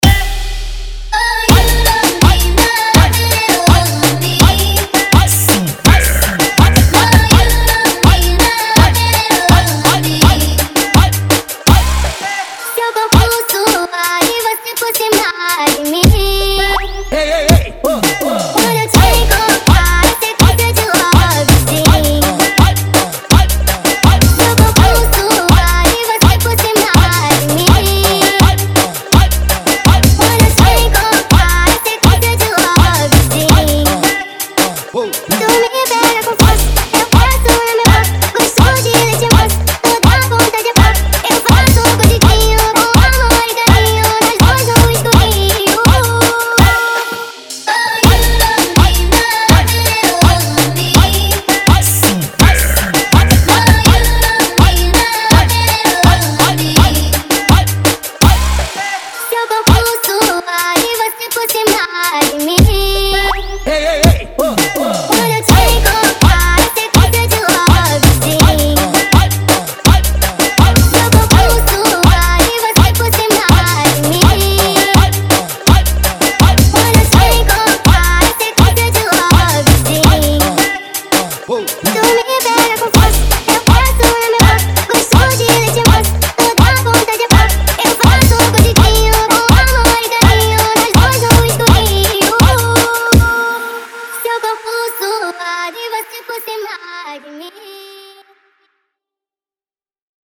Home Arrochafunk